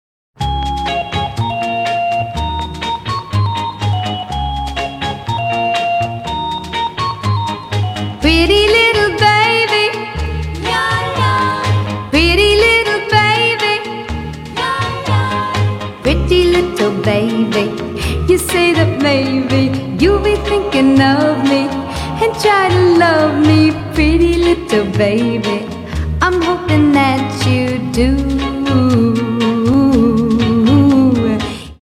милые , 60-е , tik-tok , детские , ретро , добрые